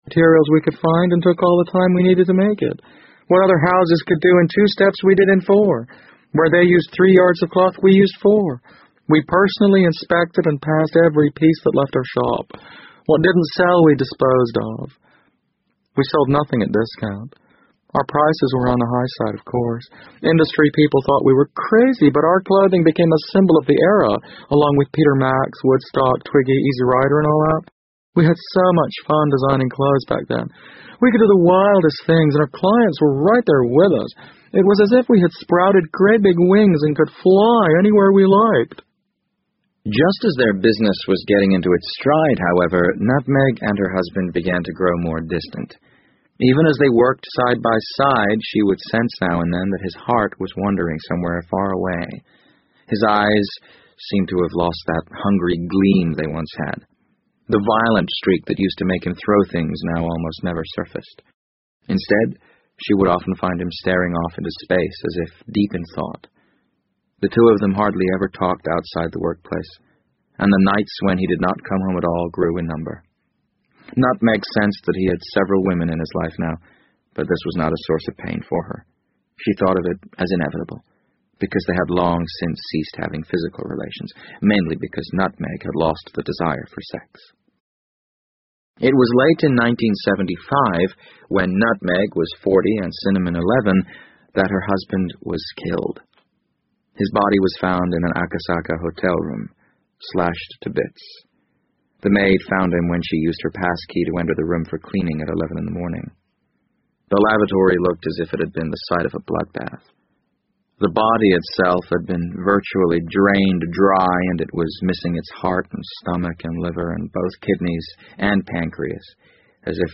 BBC英文广播剧在线听 The Wind Up Bird 012 - 11 听力文件下载—在线英语听力室